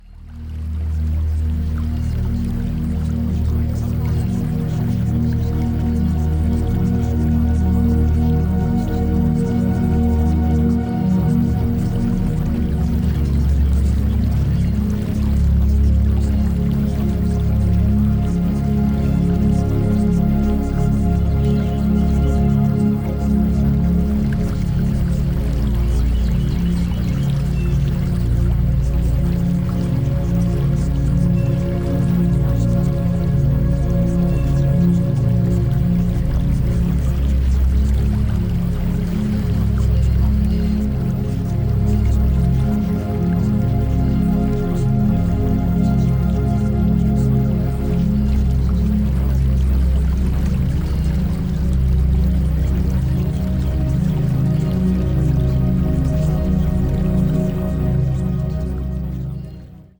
Here are samples of the 4 music mixes.